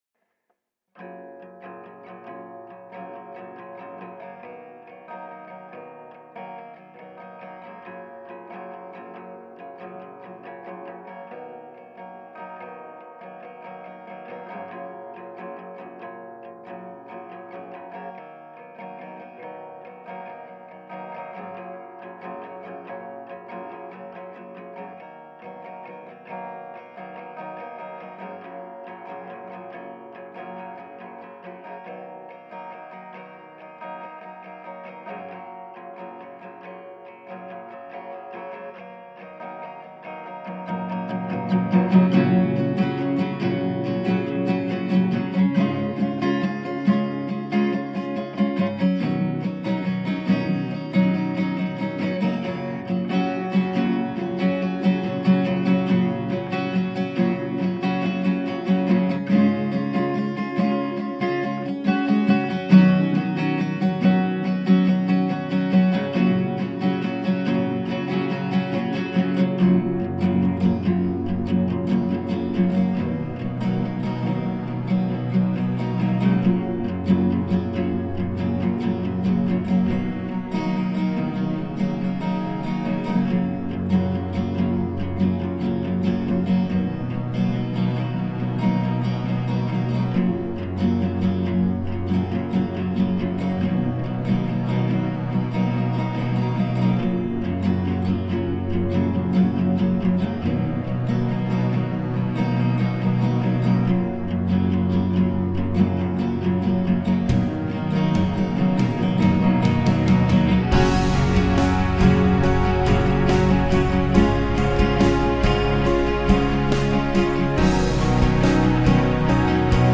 a bit of guitar a bit of strings